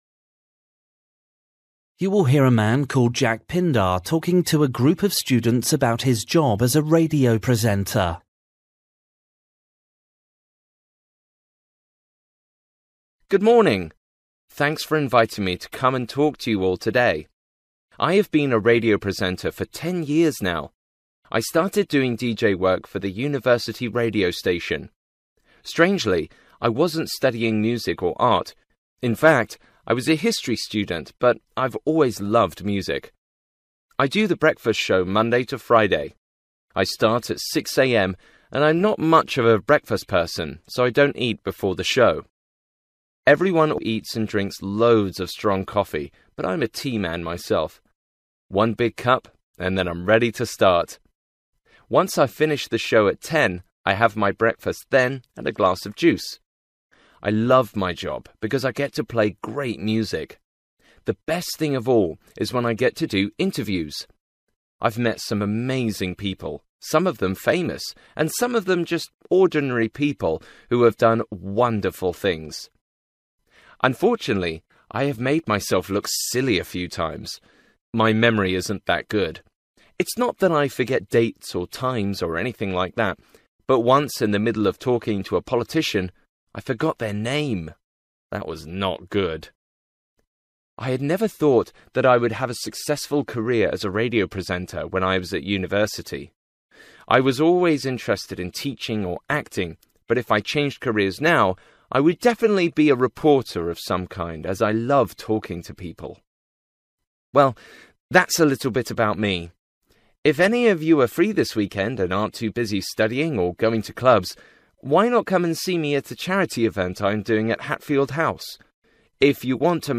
Listening: a radio presenter